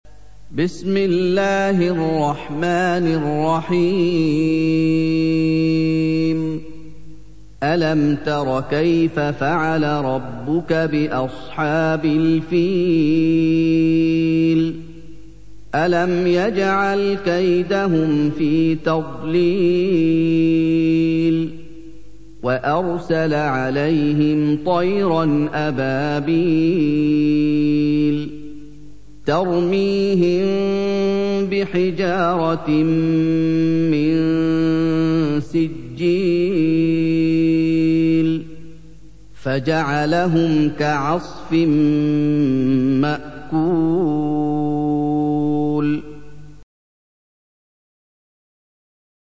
سُورَةُ الفِيلِ بصوت الشيخ محمد ايوب